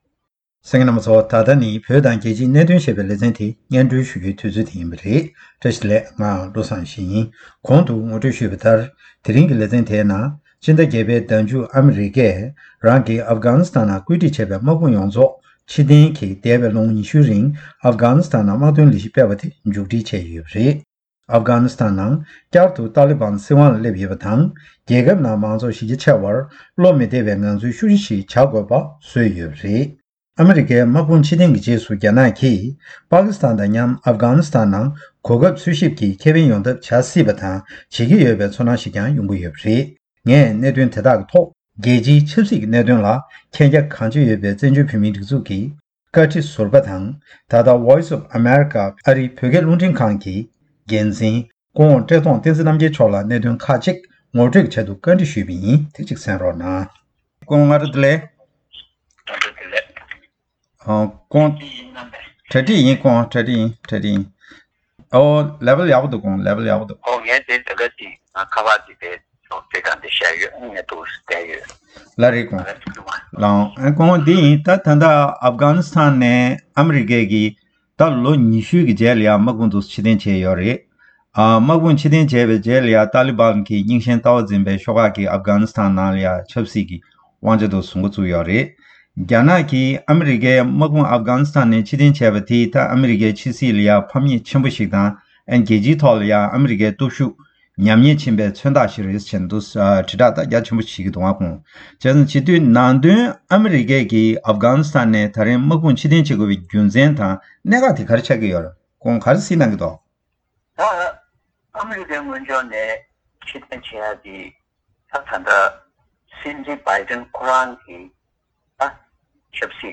གནས་དྲིས་ཞུས་པ་དེ་སྙན་སྒྲོན་ཞུས་པར་གསན་རོགས།